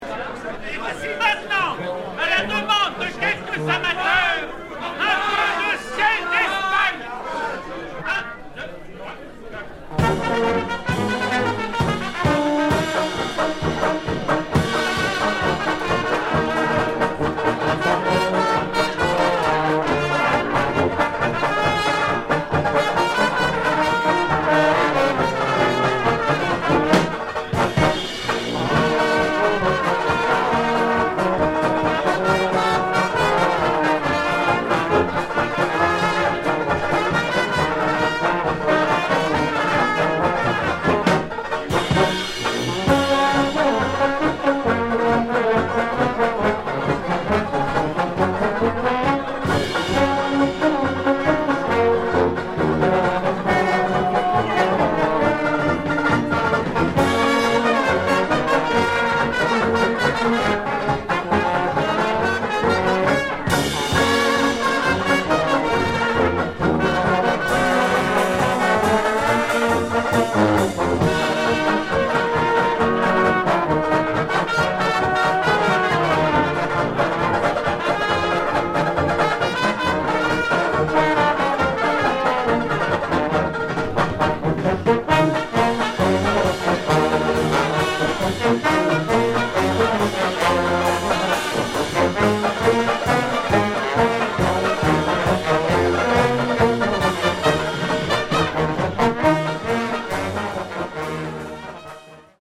Pasos dobles & exotiques